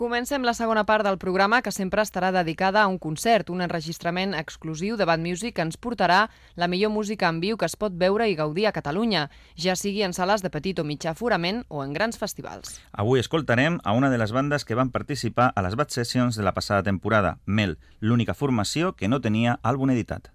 Presentació del programa especialitzat en concerts de música underground.
Musical